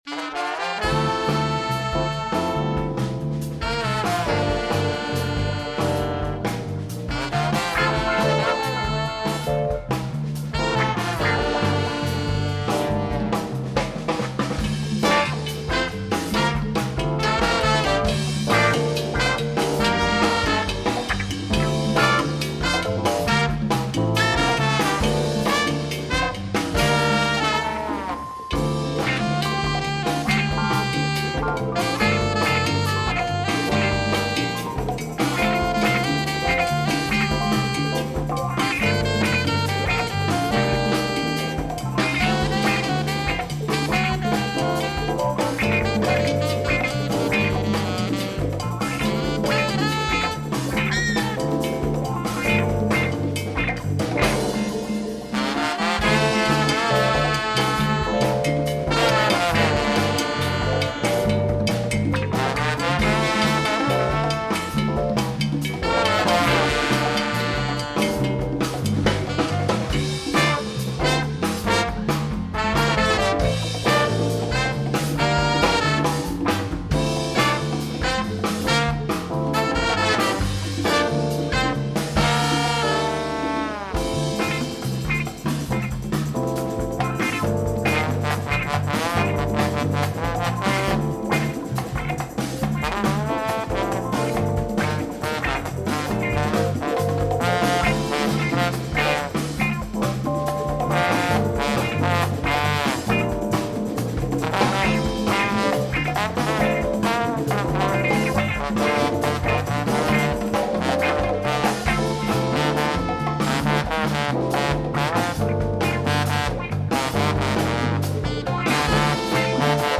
DancefloorJazz